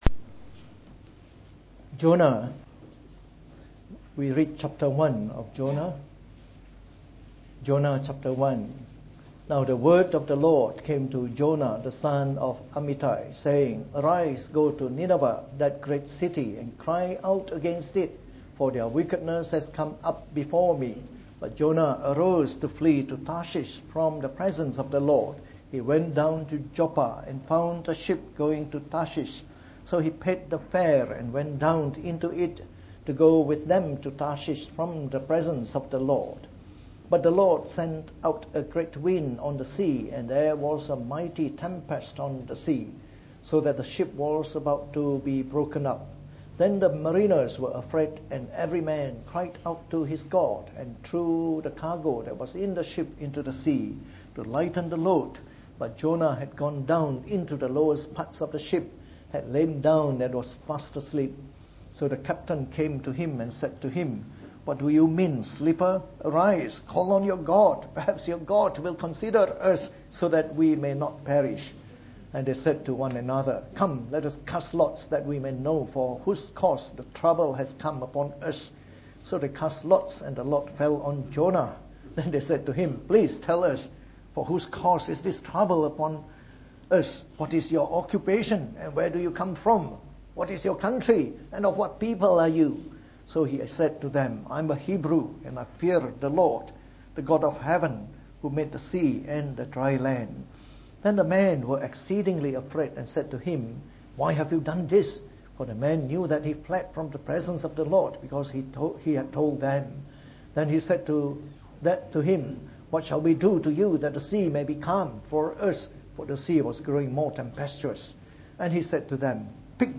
From our new series on the Book of Jonah delivered in the Morning Service.